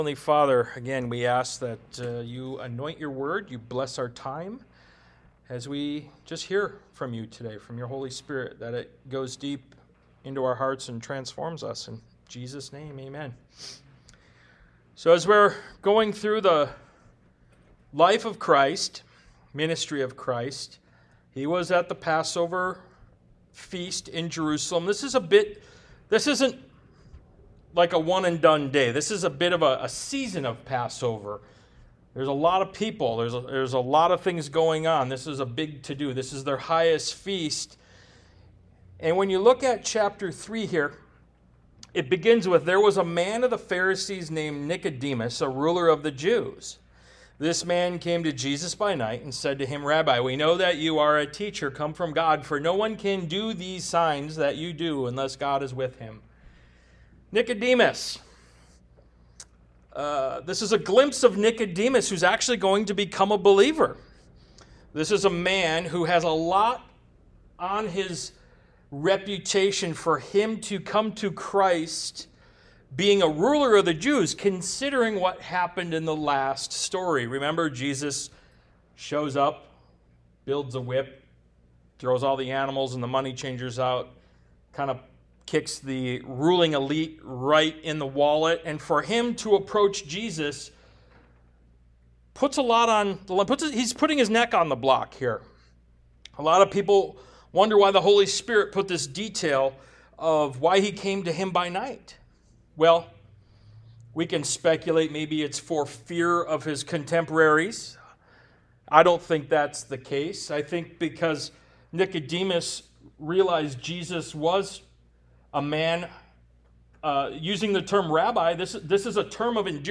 Ministry of Jesus Service Type: Sunday Morning « “Miracle Whip” Ministry of Jesus Part 5 “Are You Thirsty?”